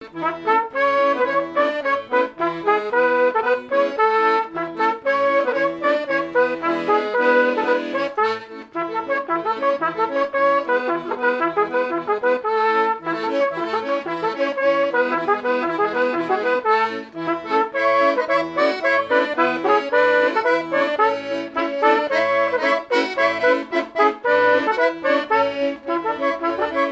airs de danses issus de groupes folkloriques locaux
Pièce musicale inédite